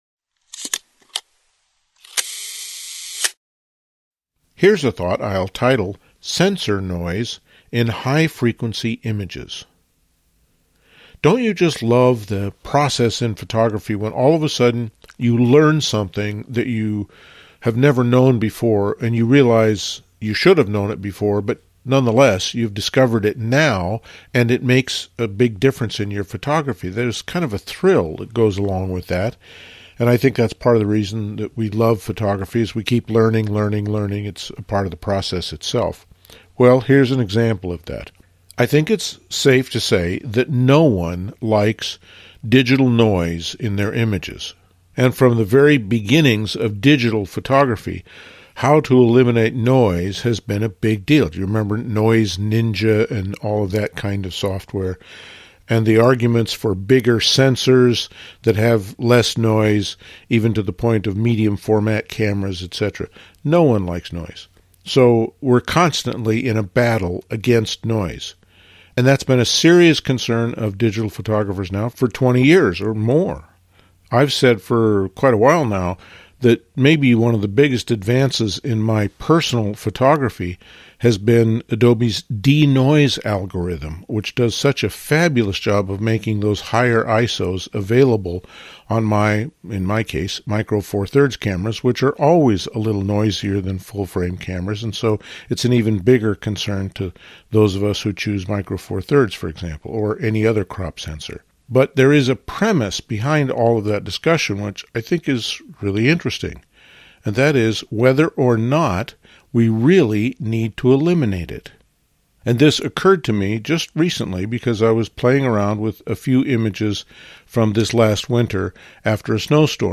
These talks focus on the creative process in fine art photography.